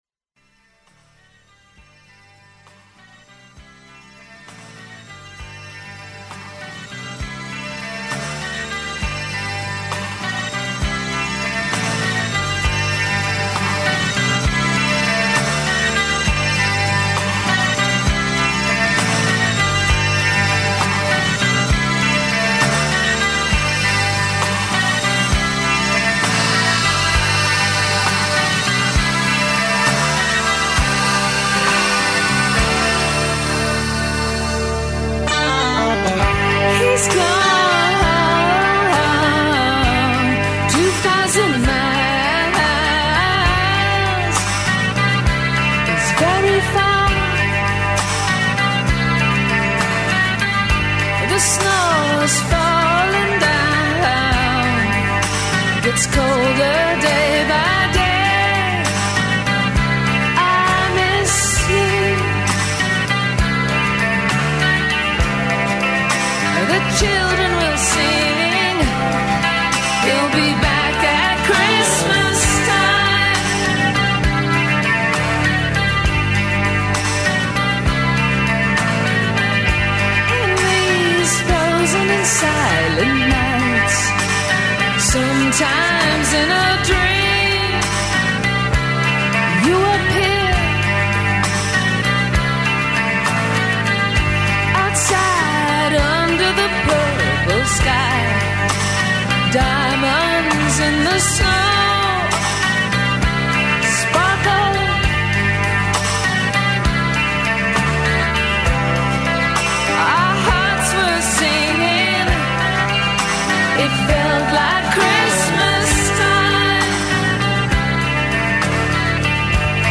Разговарамо са Бранимиром Бабићем Кебром, о плановима састава Обојени програм.